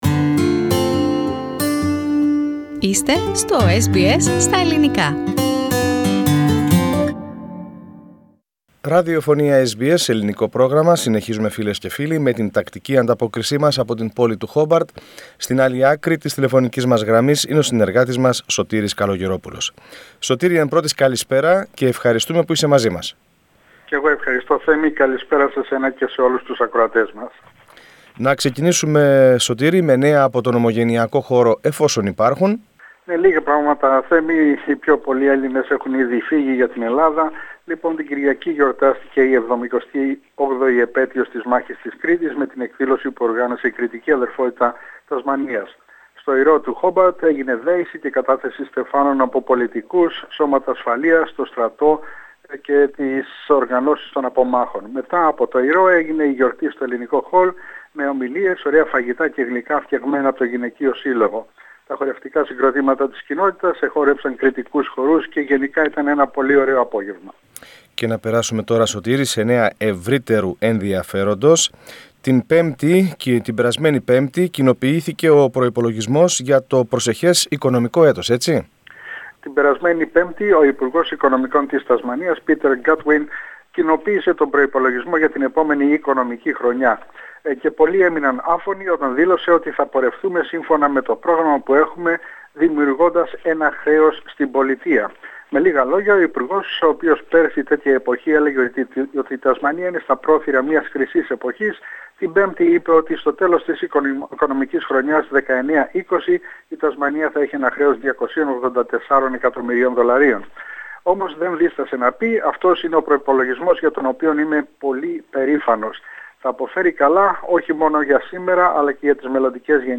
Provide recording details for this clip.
A team of cavers in Tasmania linked Niggly Cave with the Growling Swallet cave systems while setting a new record for cave diving in Australia. More on this story and other news from Tasmania in our report from Hobart.